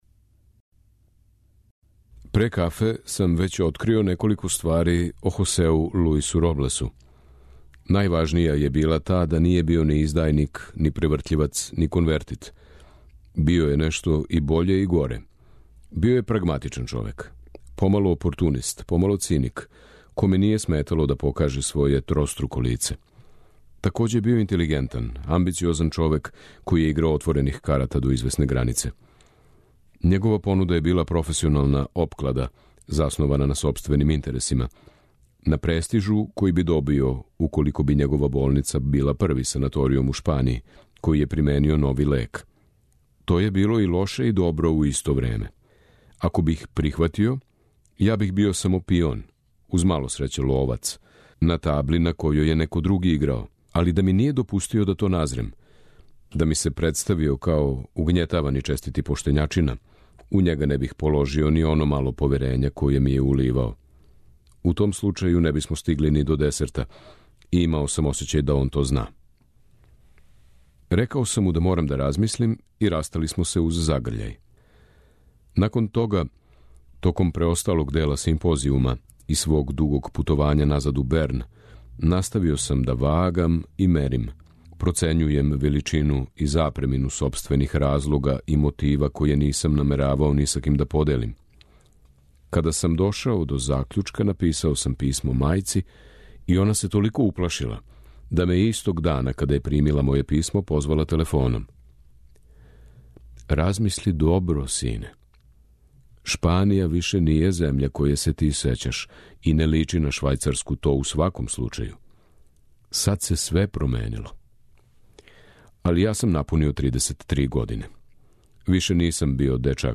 У емисији Путеви прозе, можете слушати делове романа савремене шпанске списатељице Алмудене Грандес „Франкенштајнова мајка”.